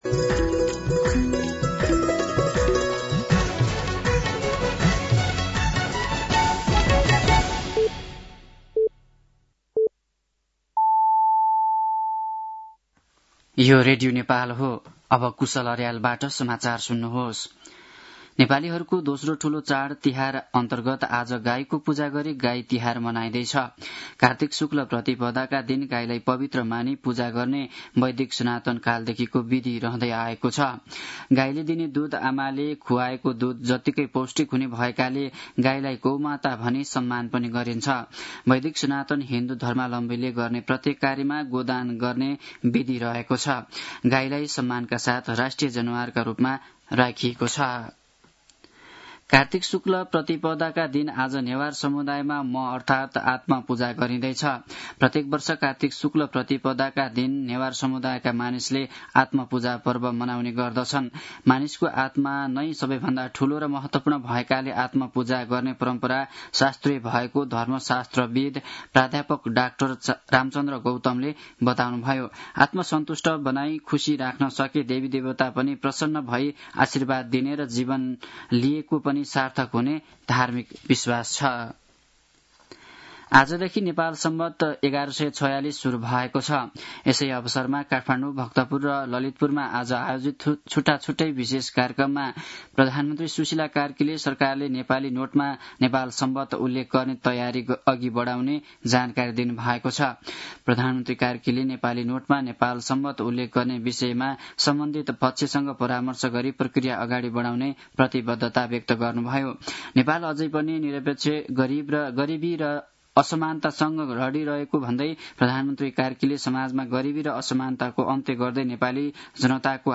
साँझ ५ बजेको नेपाली समाचार : ५ कार्तिक , २०८२